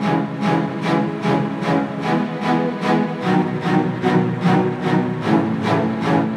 As it is, it loops “not too badly”, the length is right so it maintains a steady rhythm when looped, but it is not exactly “seamless”.
In order to avoid clicks at the join, the person that made this loop just applied fades at the ends, but when looped, this is certainly noticeable as a drop in volume.